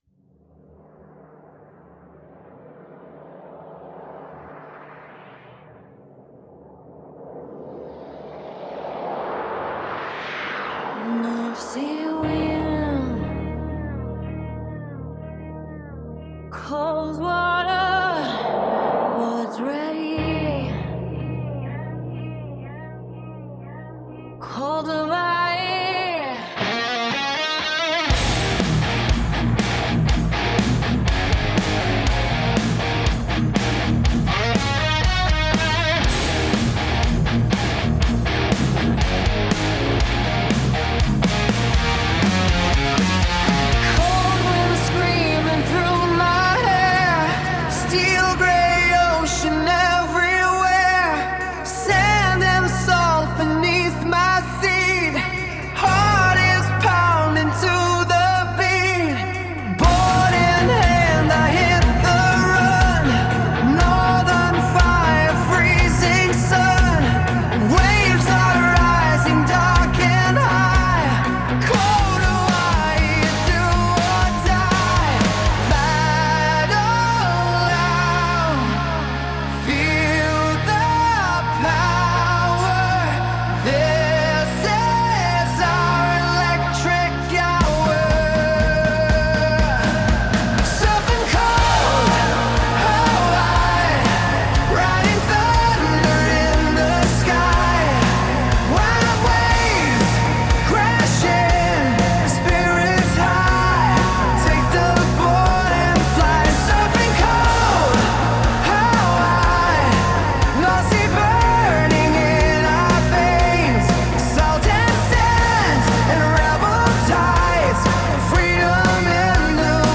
🎸 Original Song  ·  Hard Rock
🎸 Wild guitar — rides like the waves